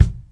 kick 10.wav